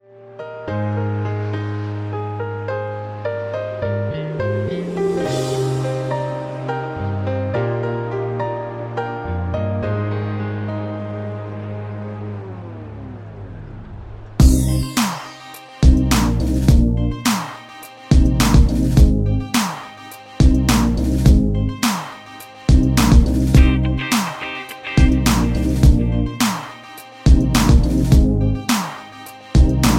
Cm
MPEG 1 Layer 3 (Stereo)
Backing track Karaoke
Pop, Duets, 2010s